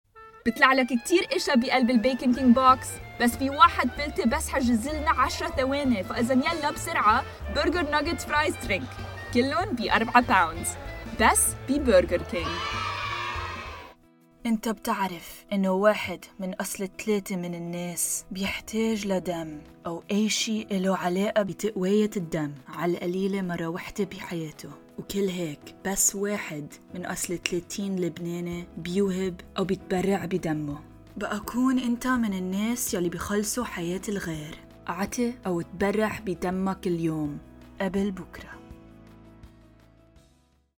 Female / 20s, 30s / American, Arabic, Danish, English, French, Lebanese / American